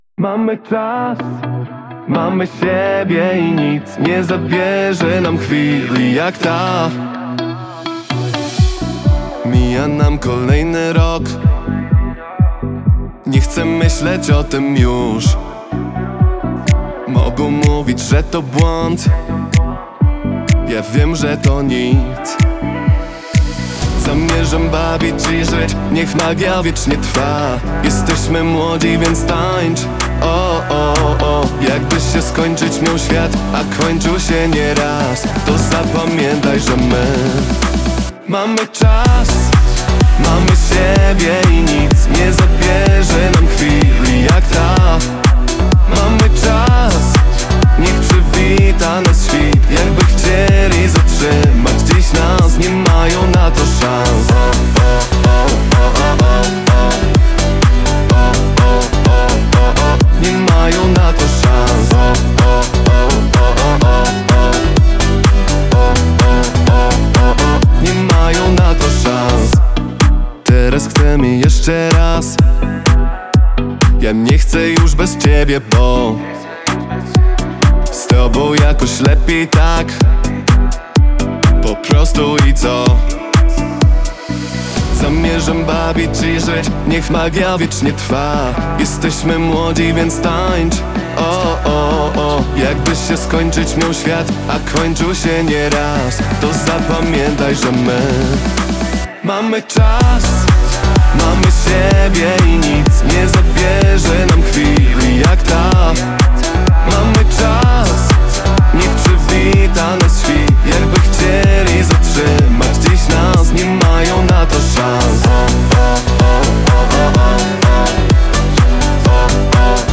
Singiel (Radio)
nowy, popowo-taneczny utwór